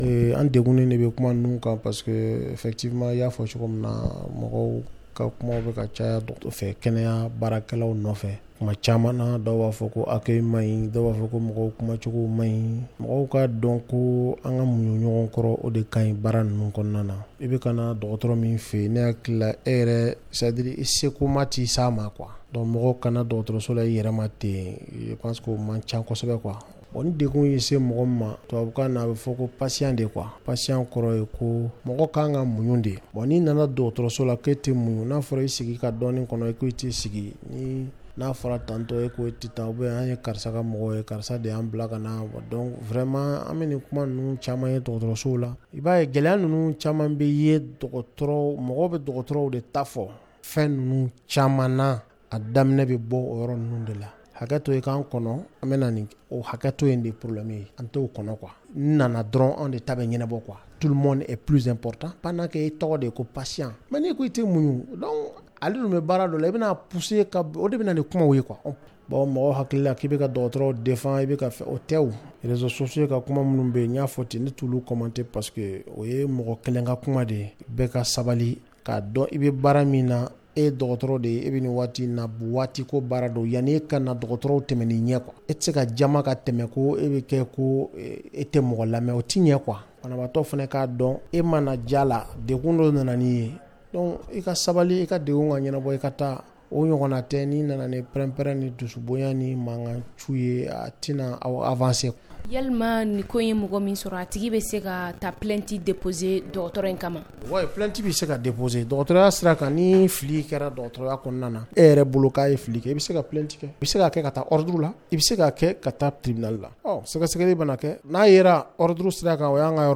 répond aux questions